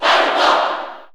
Crowd cheers (SSBU) You cannot overwrite this file.
Falco_Cheer_Japanese_SSB4_SSBU.ogg